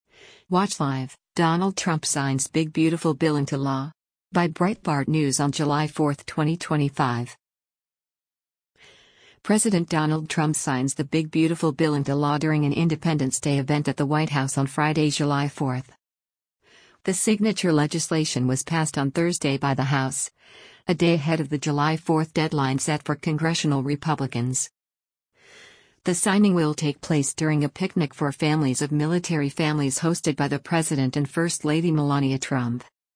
President Donald Trump signs the Big Beautiful Bill into law during an Independence Day event at the White House on Friday, July 4.
The signing will take place during a picnic for families of military families hosted by the president and First Lady Melania Trump.